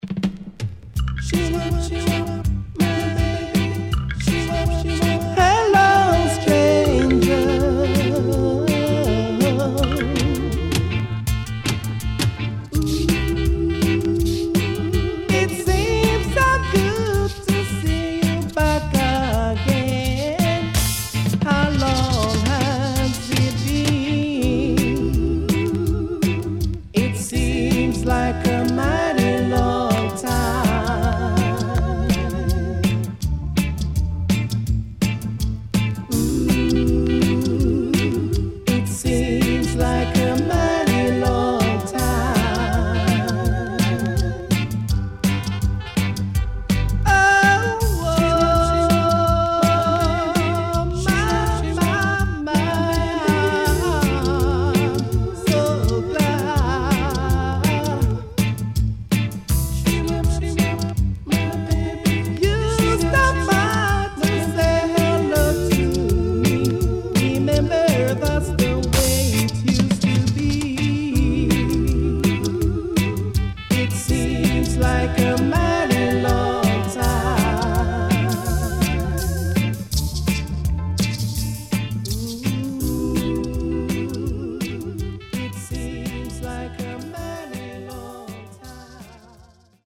83年マイナー調からGood Mediumまで良曲多数